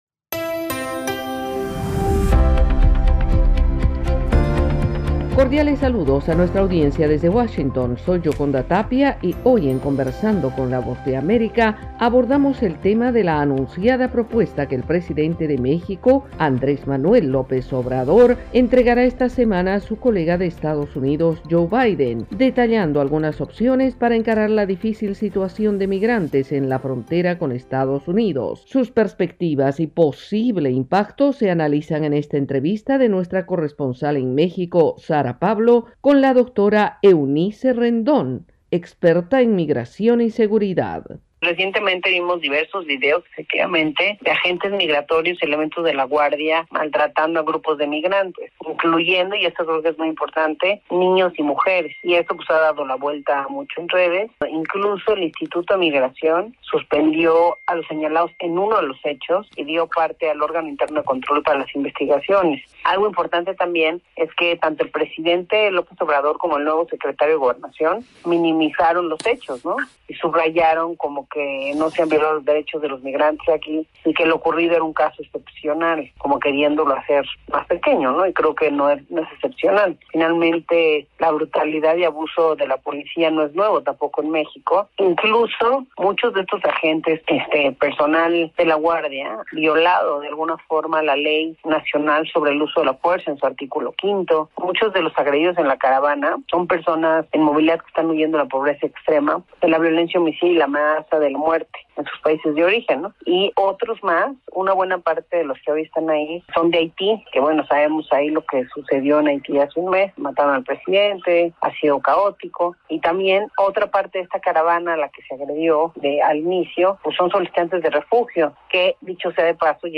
Conversamos con la experta mexicana en migración y seguridad